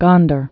(gŏndər, -där) also Gon·der (-dər)